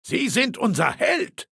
Datei:Maleold01 ms06fin hello 00049e25.ogg